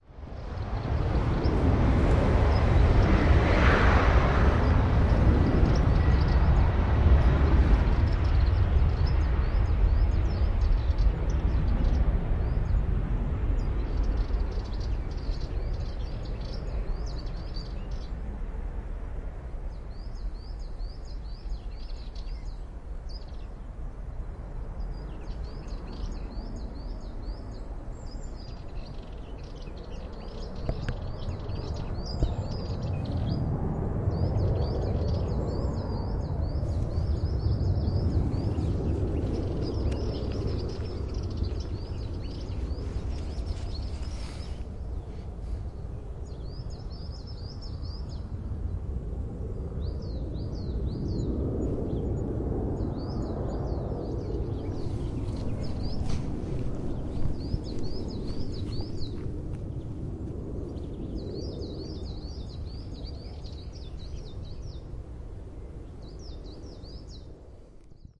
铁锹煤
描述：一大堆木炭被铲子耙得七零八落，尘土飞扬。
标签： 分散 撒上 污垢 灰尘 木炭 秋天
声道立体声